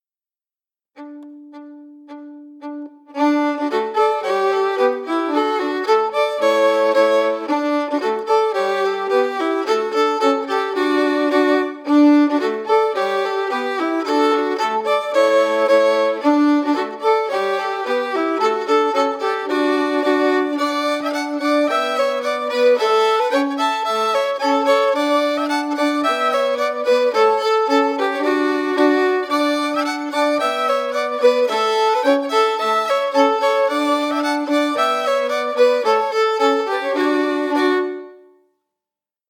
Key: D
Form: Polka
Harmony emphasus
Region: Wales
Hunting-The-Hare-audio-file-harmonies-emphasized.mp3